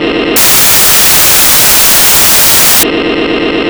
MANUAL DIESEL SOUNDS WITHOUT HORN, IDEAL FOR BASEBOARD
TOP SPEED AT THREE QUARTERS
VOLUME